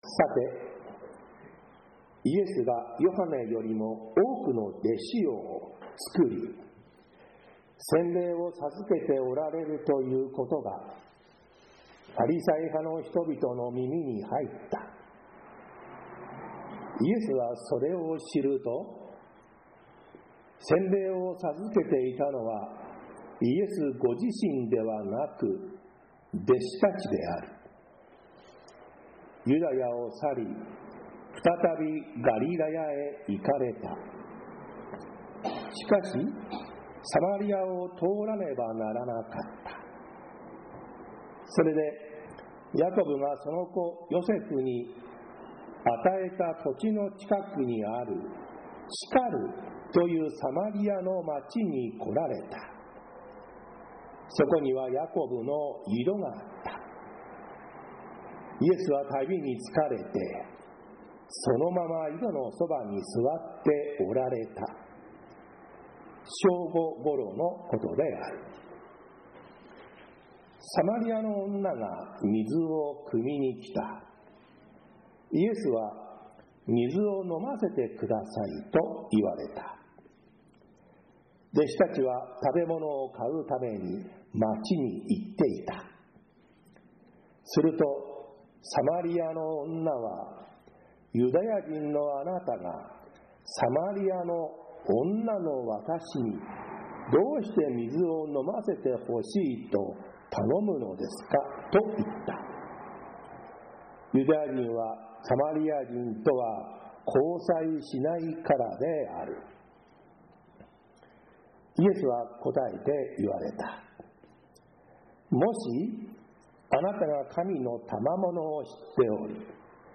渇かない水 宇都宮教会 礼拝説教